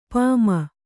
♪ pāma